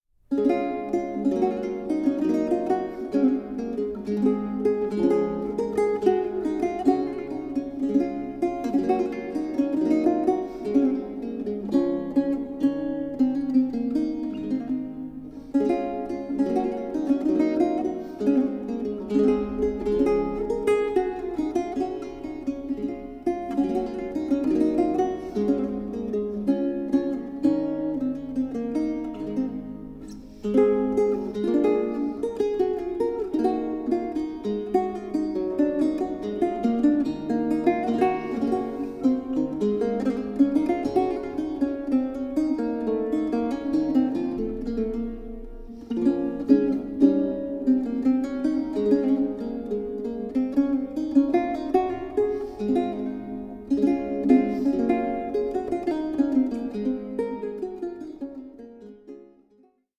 baroque guitar